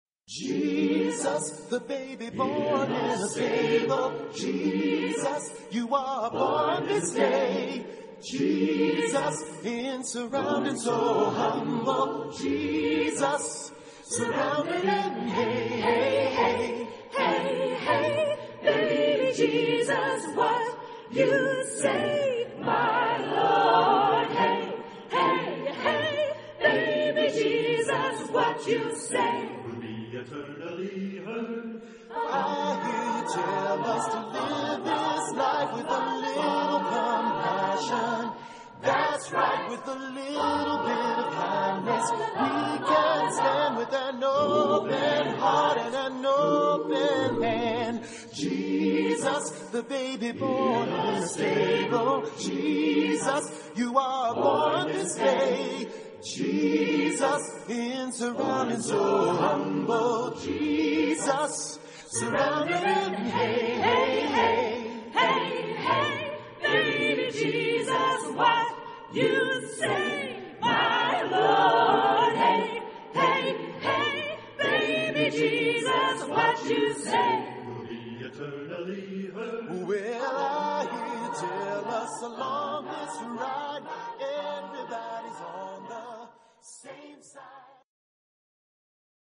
Tonalité : si bémol majeur